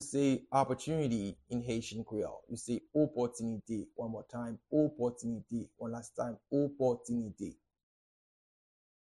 How-to-say-Opportunity-in-Haitian-Creole-Opotinite-pronunciation-by-native-Haitian-teacher.mp3